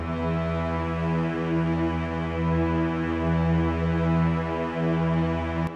Orchestra
e4.wav